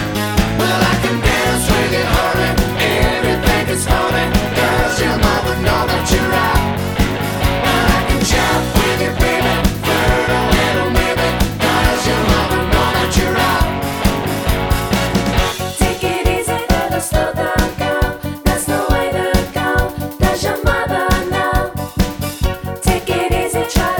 One Semitone Down Pop (1970s) 3:07 Buy £1.50